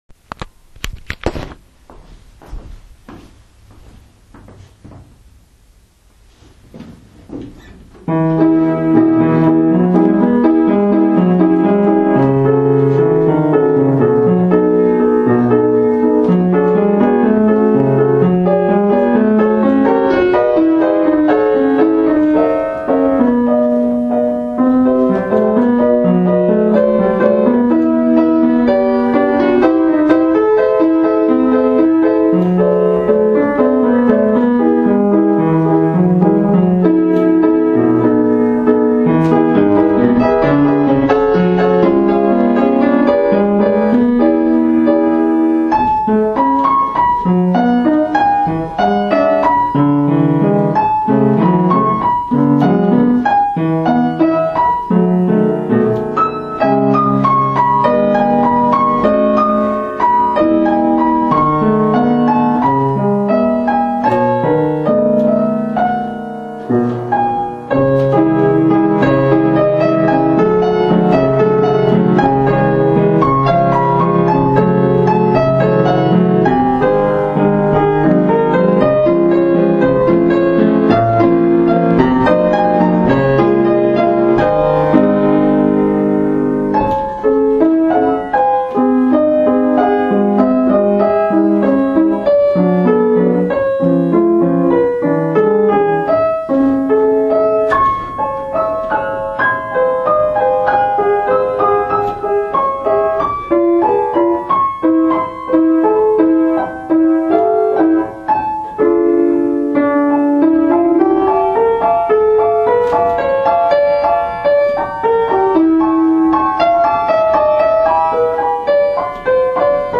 -Télécharger Idyle, une sonate du IXX siecle . Cette création de piano fut composée parmi tant d autres, par le grand père de Saint-Chamas.